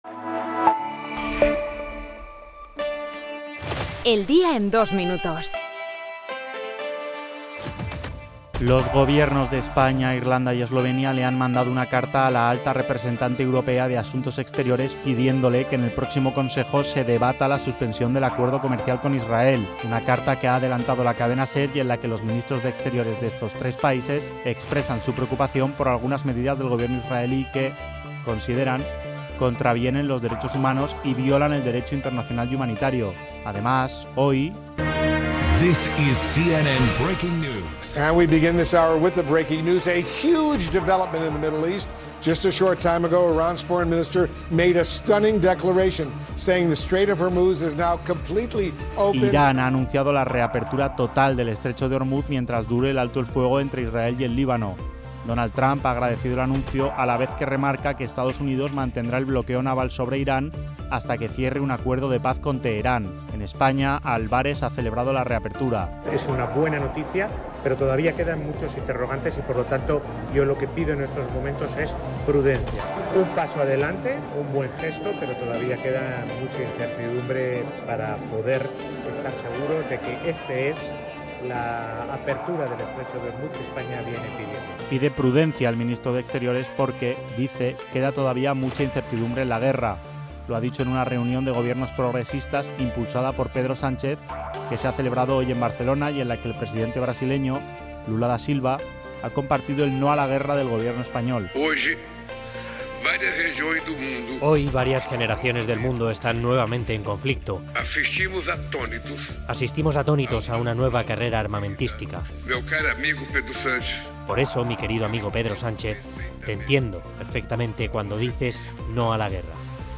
El resumen de las noticias del día